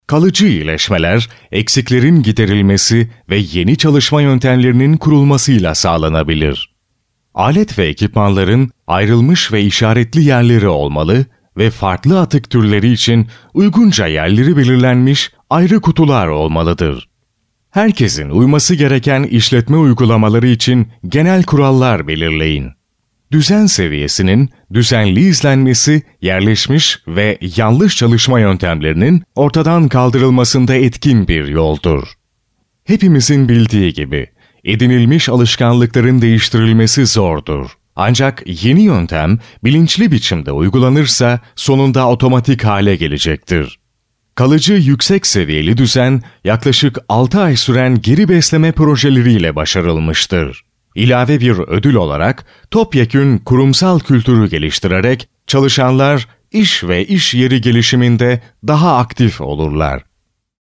male voice
Kein Dialekt
Sprechprobe: Werbung (Muttersprache):